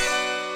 GUnit Synth6.wav